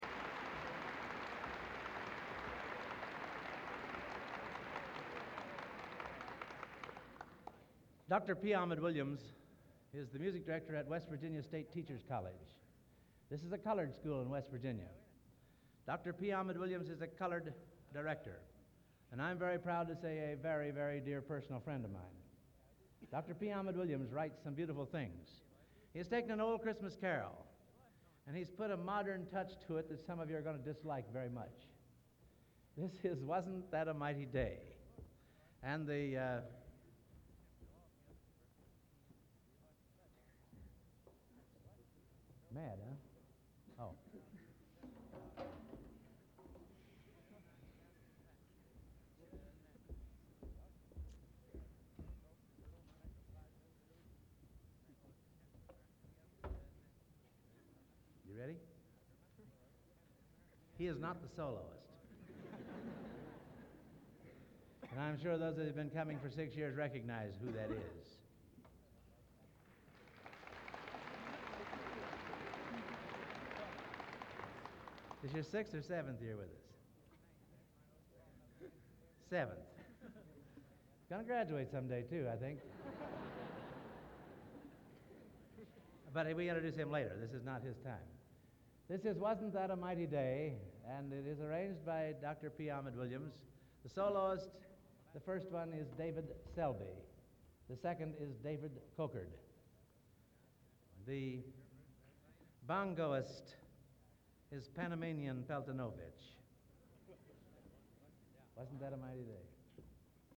Collection: Hammond Concert, 1960
Genre: | Type: Director intros, emceeing